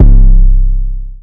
808 5 [ pyrex 1 ].wav